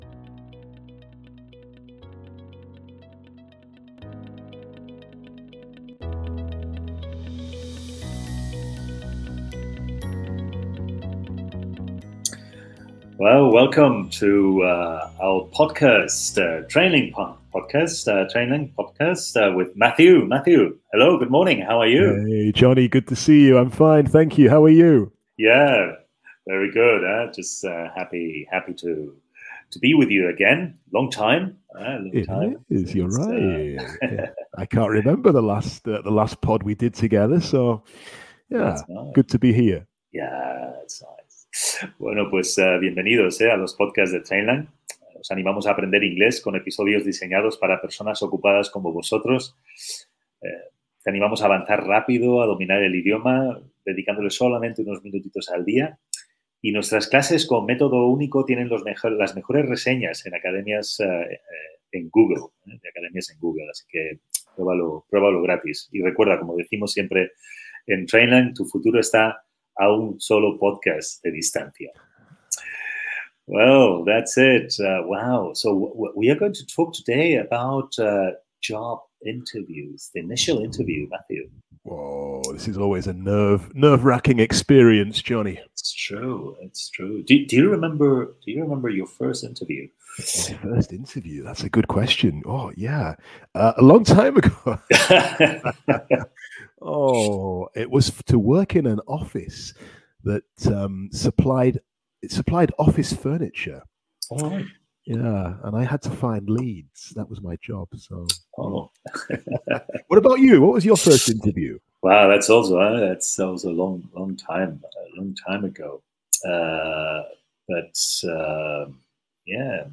Escucha una entrevista simulada y aprende a comunicarte de manera clara y profesional.